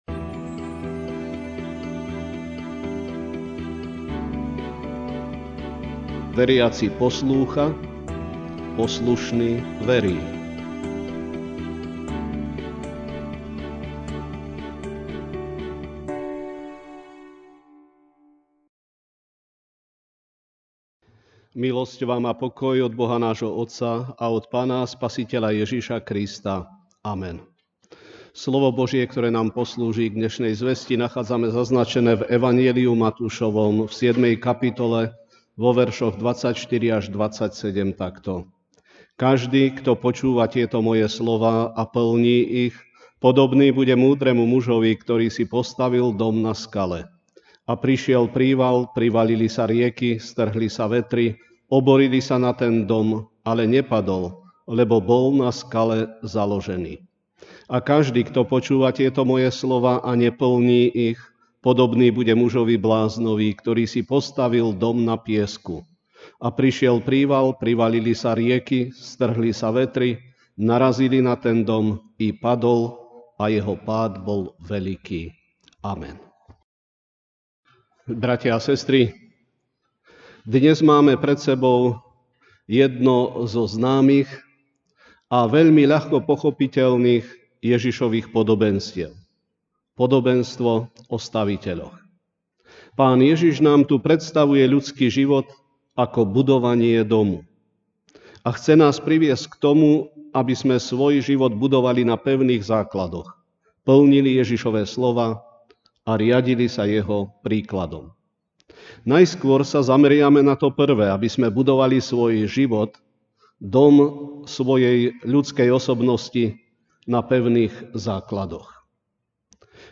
Večerná kázeň: Symbióza skutkov a viery (Mt 7, 24-27) Každý, kto počúva tieto moje slová a plní ich, podobný bude múdremu mužovi, ktorý si postavil dom na skale.